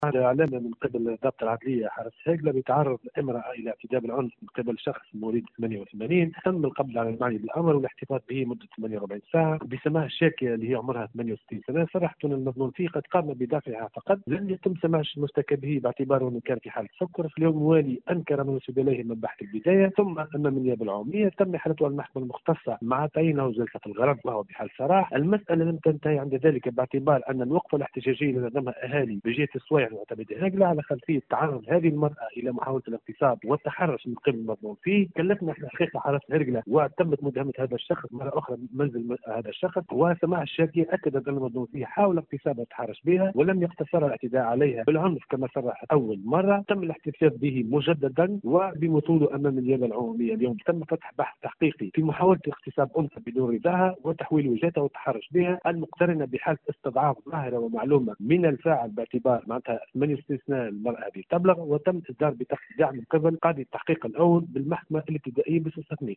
في تصريح ل “ام اف ام”